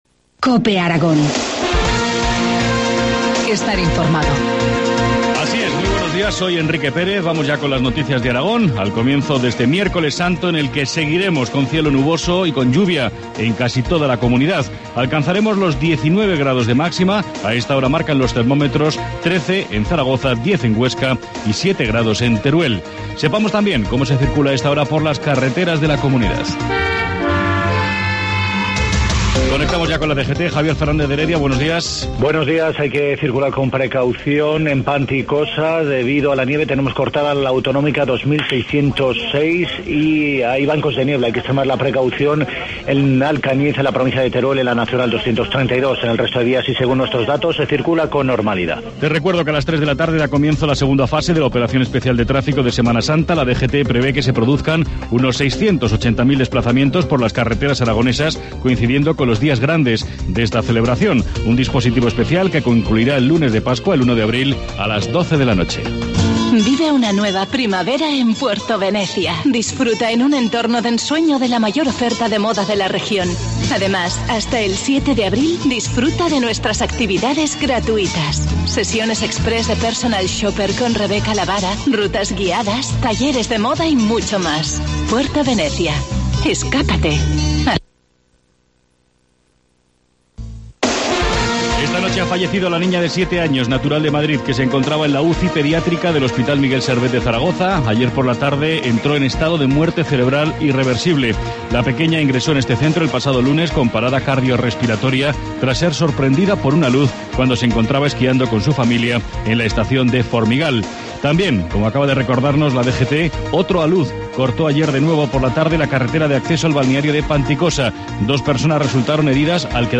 Informativo matinal, miércoles 27 de marzo, 7.25 horas